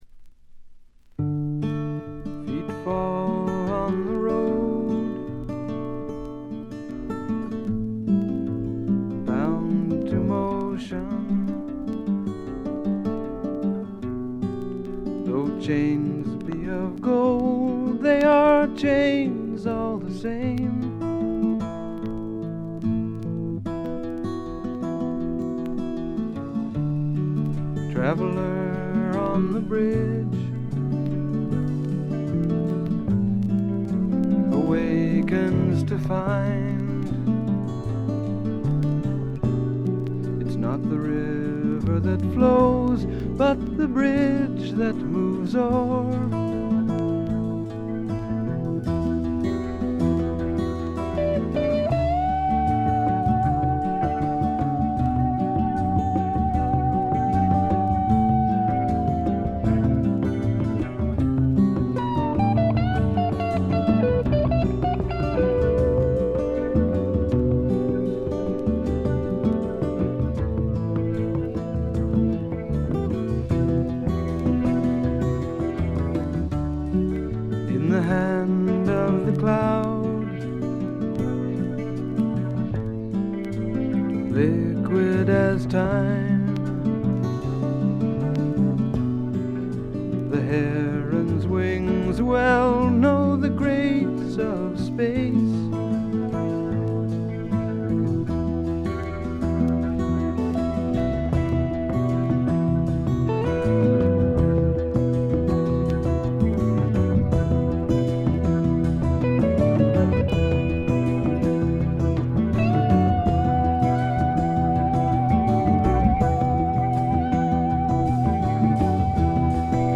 散発的なプツ音少し。
試聴曲は現品からの取り込み音源です。